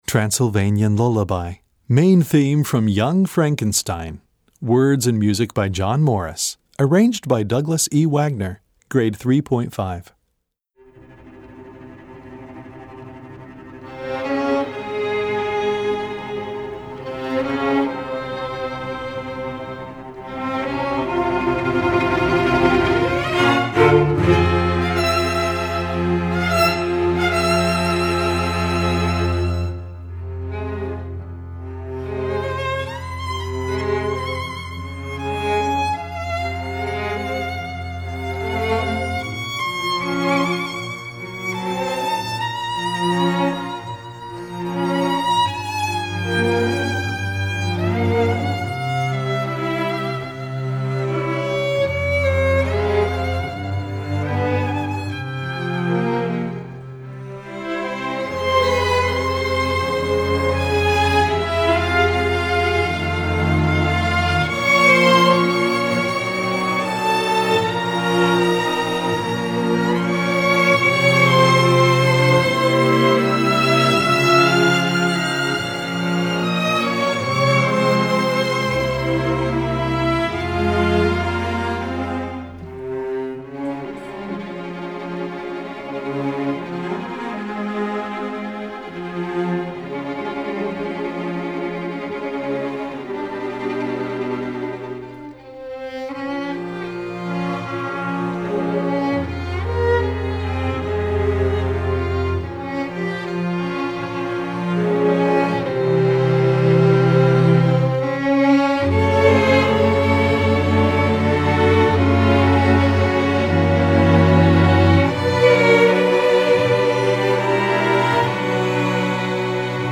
Gattung: Streichorchester
Besetzung: Streichorchester
heart-rending theme
string orchestra